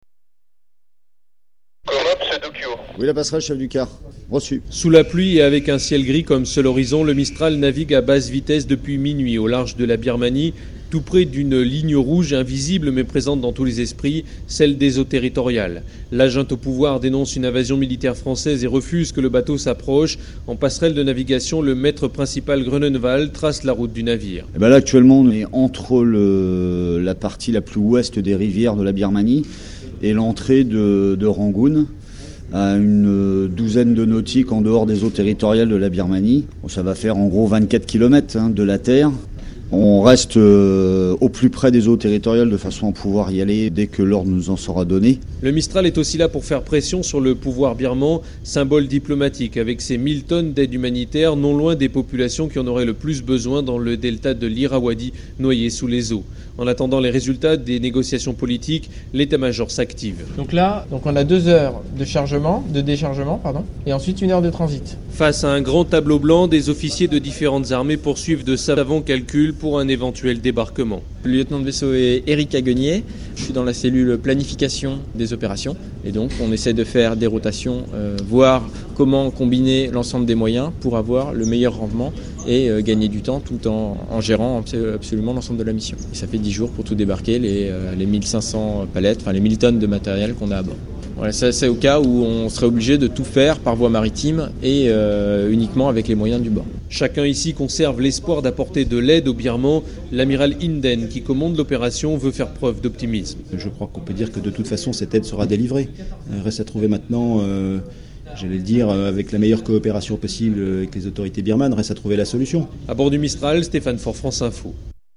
Reportage France Info